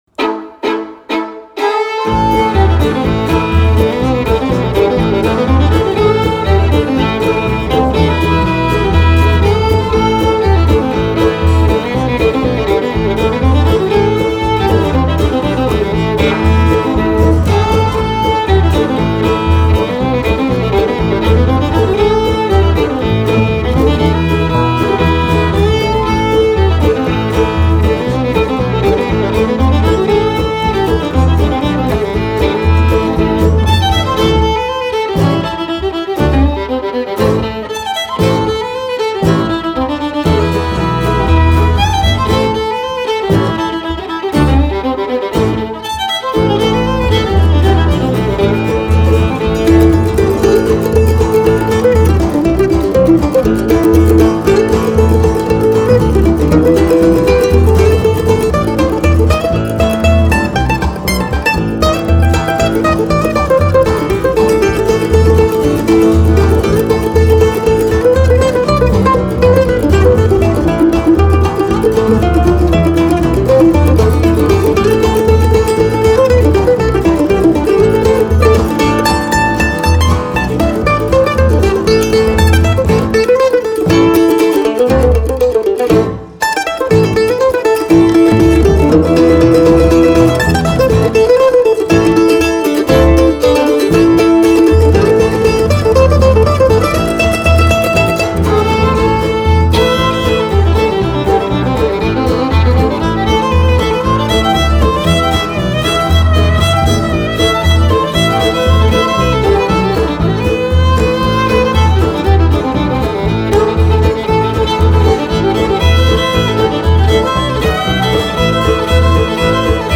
progressive wood-tone music